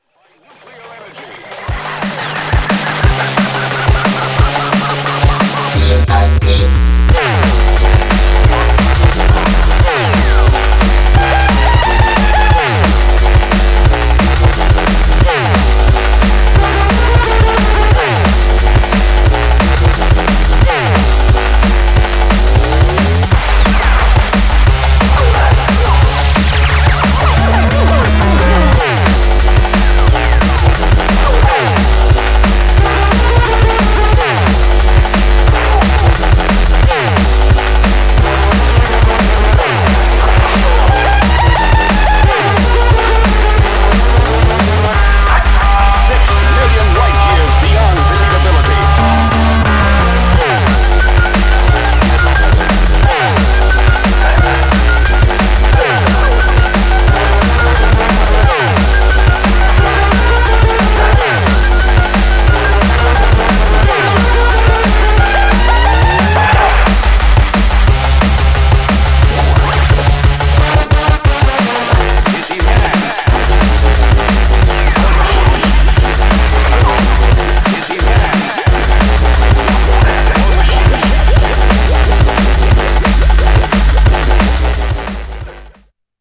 Styl: Techno Vyd�no